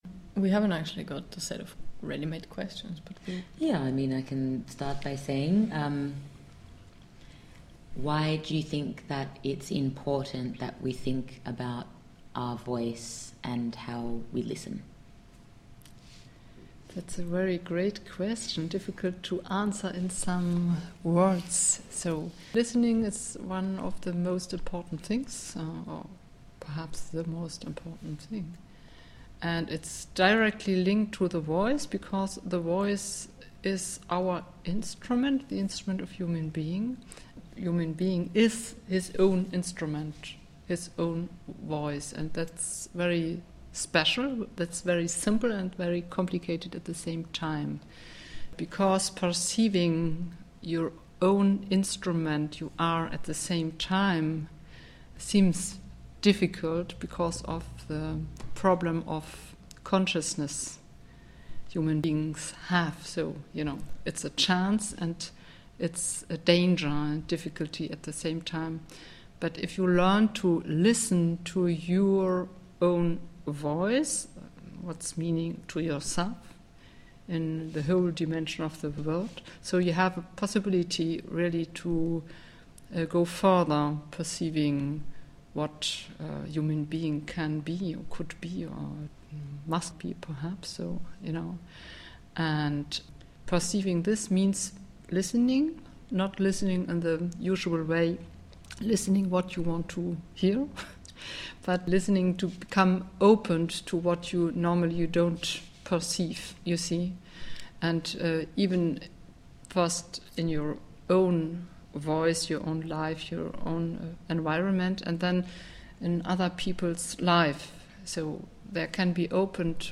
How revolution might sound. Radio feature, interview, essays.
for the Dissident Island Radio London: